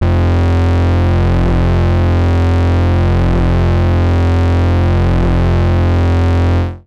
TS Synth Bass_5.wav